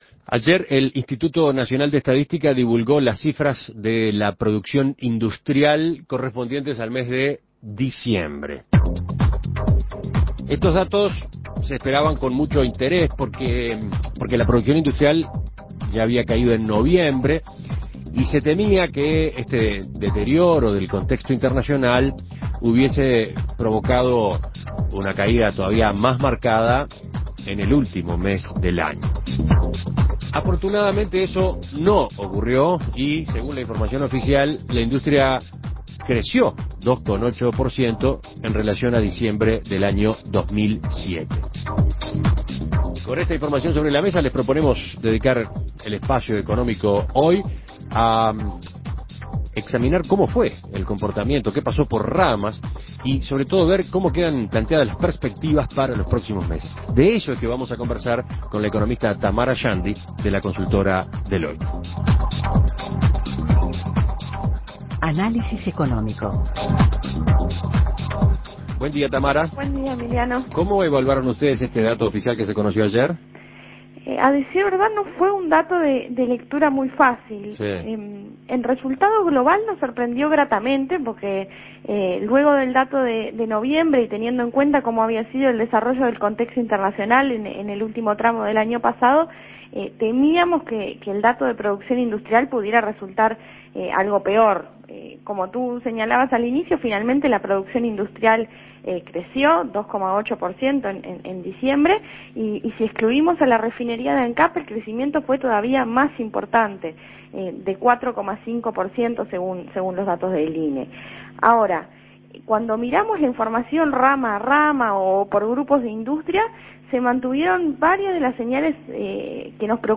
Análisis Económico ¿Qué señales se desprenden de los datos de crecimiento industrial que divulgó el INE?